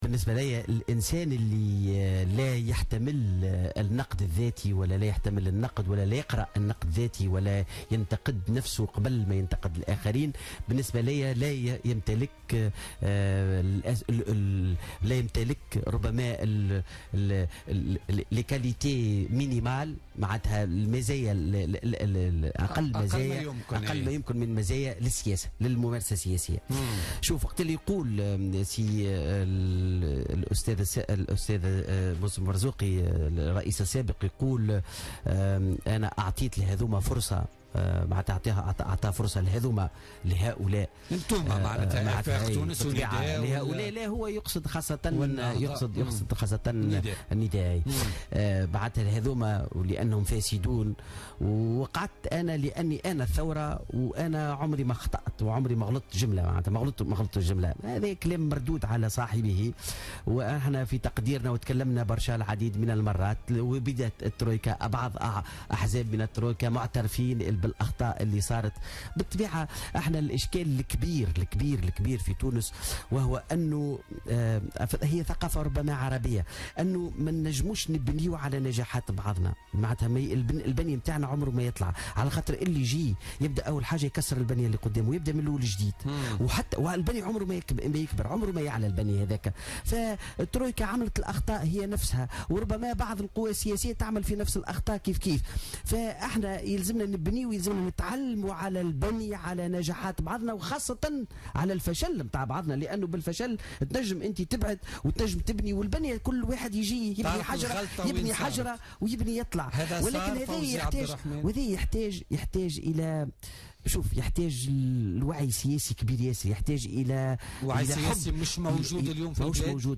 علق عضو المكتب السياسي لحزب آفاق تونس فوزي عبد الرحمان ضيف برنامج بوليتكا لليوم الاثنين 19 سبتمبر 2016 على خطاب المرزوقي الذي بثته قناة التاسعة مؤخرا.